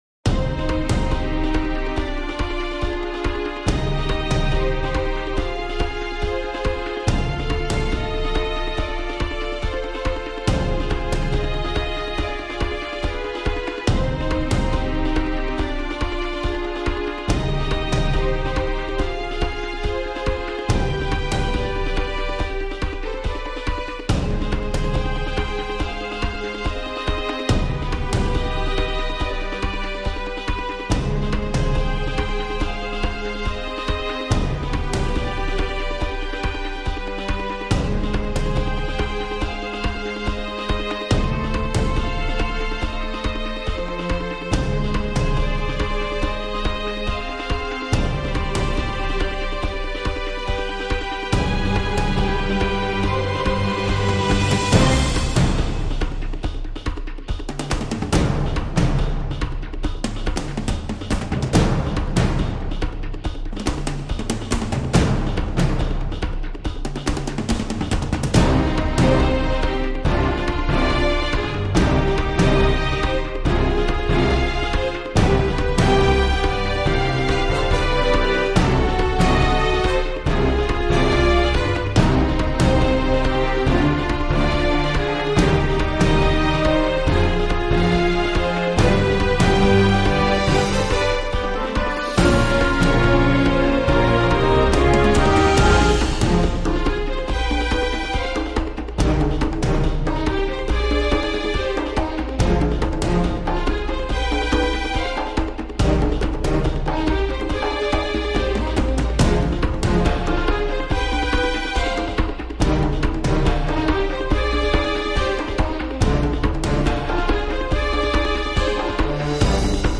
- swashbuckling adventure music